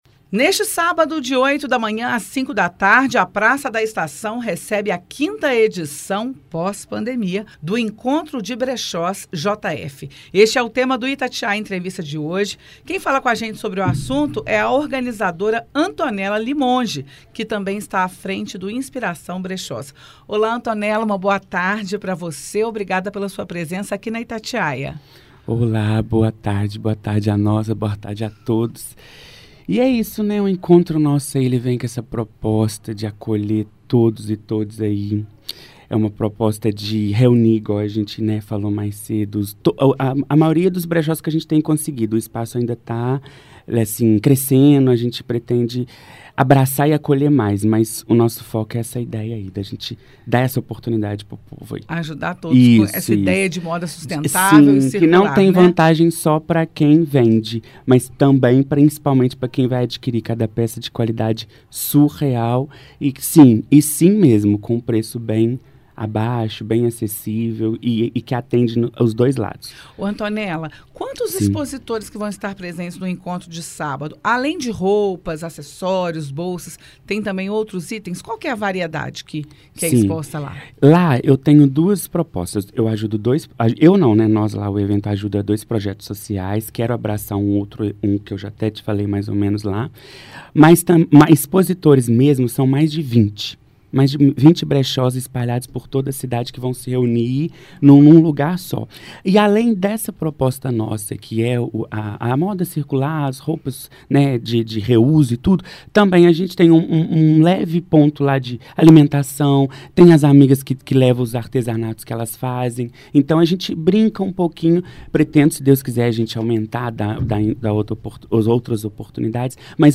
Itatiaia-Entrevista-Encontro-de-Brechos-JF.mp3